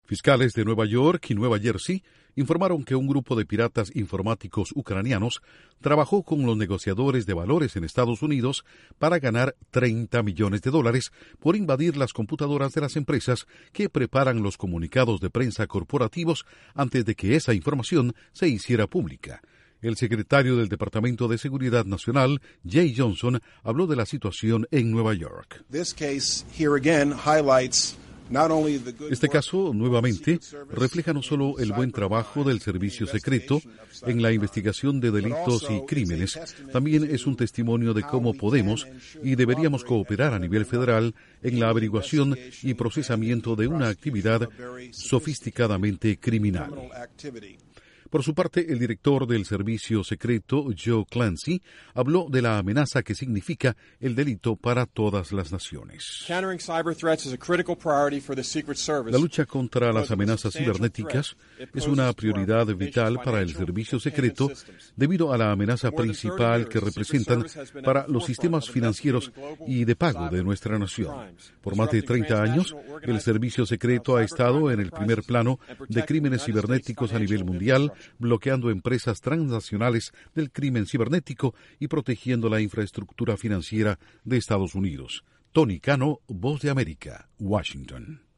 El FBI da a conocer que desarticuló a un grupo de piratas cibernéticos que estaba vendiendo información, sustraída a una compañía que preparaba comunicados de prensa, a negociadores de bolsa. Informa desde la Voz de América en Washington